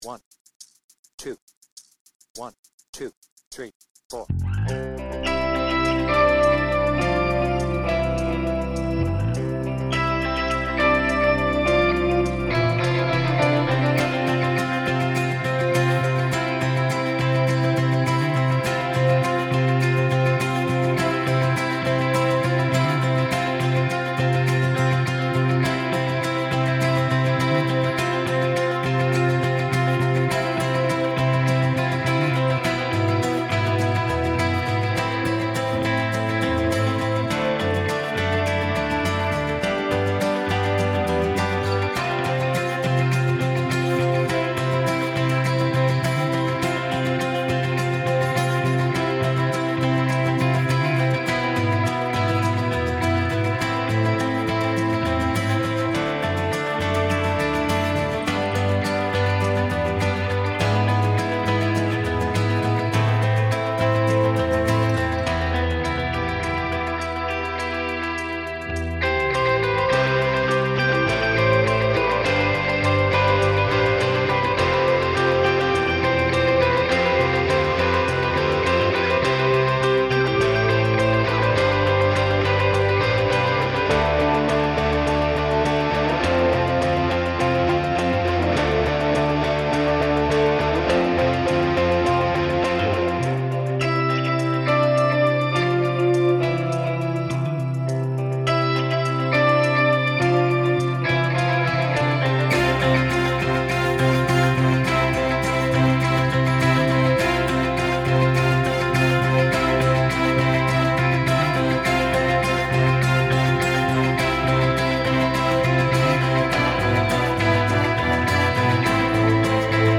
BPM : 103
Tuning : E
Without vocals
Based on the album version